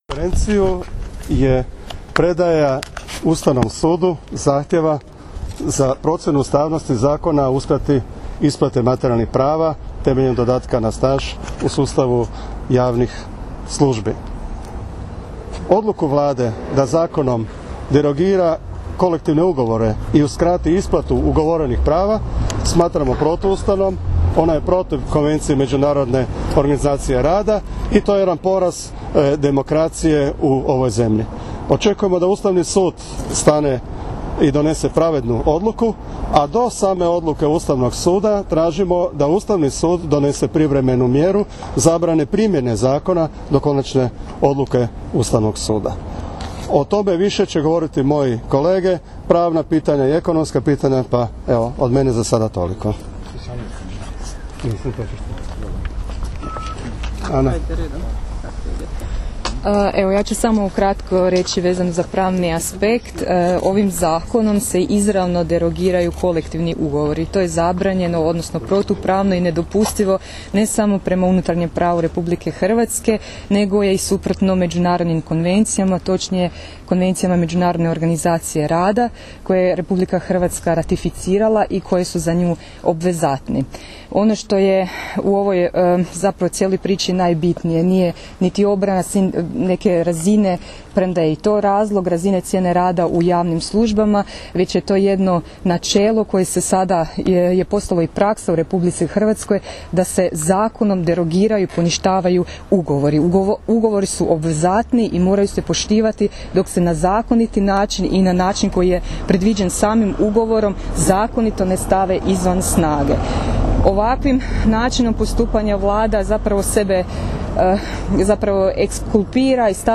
Povodom podnošenja Prijedloga za ocjenu ustavnosti Zakona o uskrati prava na uvećanje plaće po osnovi ostvarenih godina radnog staža (4, 8 i 10%), Matica hrvatskih sindikata održala je konferenciju za medije pred zgradom Ustavnog suda.